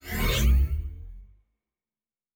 Sci-Fi Sounds / Doors and Portals / Teleport 5_2.wav
Teleport 5_2.wav